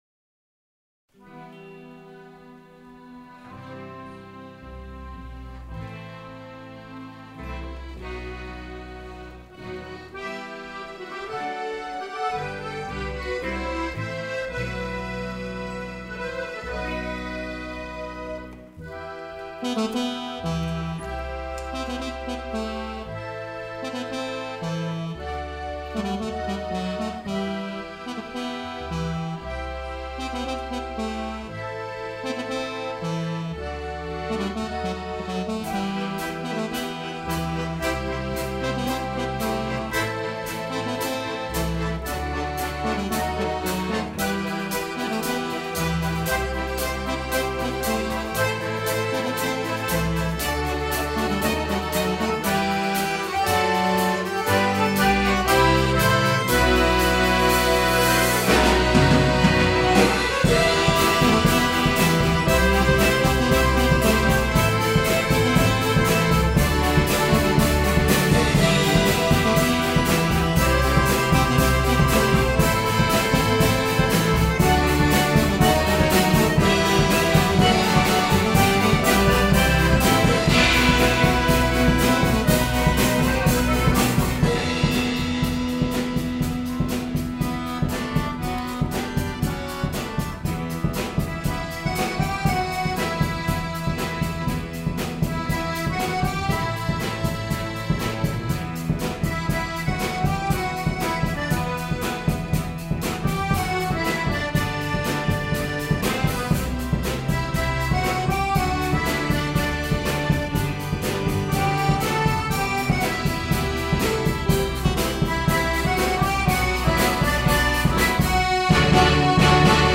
2010 – Akkordeonorchester Neustadt bei Coburg e. V.